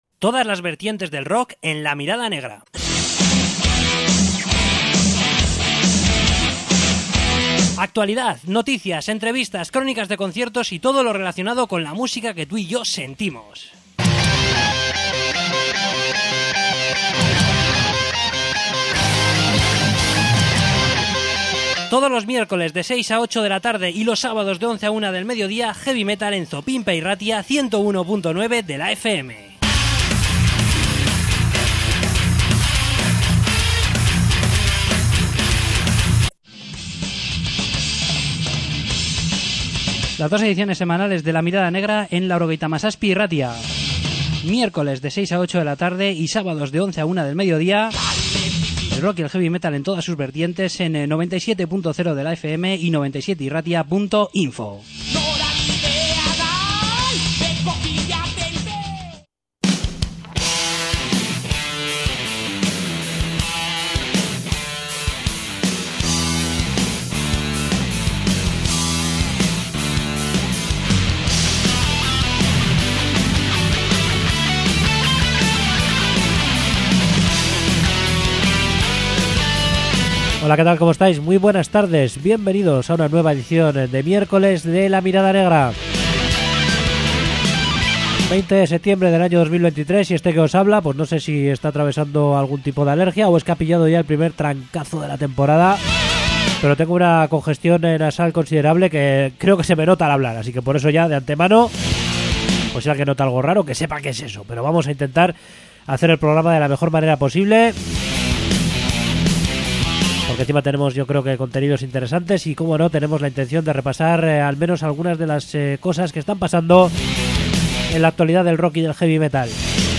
Entrevista con Azrael
Entrevista con Giant Rev